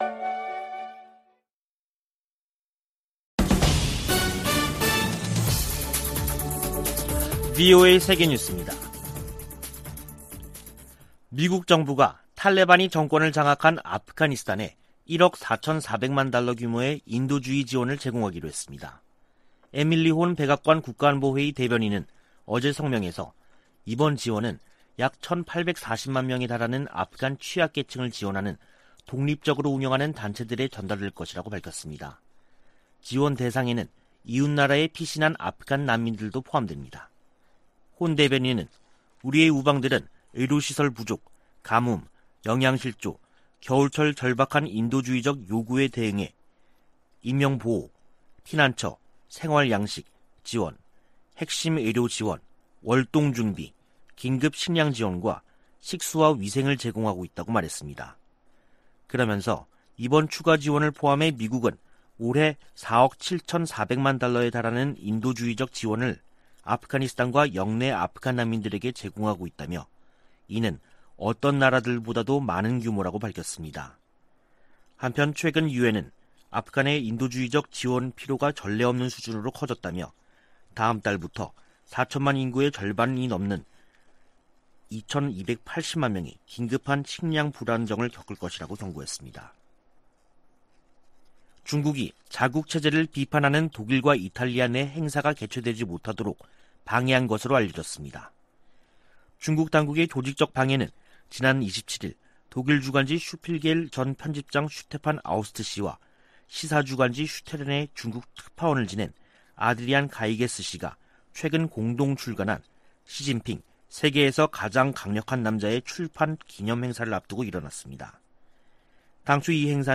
VOA 한국어 간판 뉴스 프로그램 '뉴스 투데이', 2021년 10월 29일 2부 방송입니다. 북한 신의주와 중국 단둥간 철도 운행이 다음달 재개될 가능성이 있다고 한국 국가정보원이 밝혔습니다. 유럽연합이 17년 연속 유엔총회 제3위원회에 북한 인권 상황을 규탄하는 결의안을 제출했습니다. 유엔총회 제1위원회에서 북한 핵과 탄도미사일 관련 내용 포함 결의안 3건이 채택됐습니다.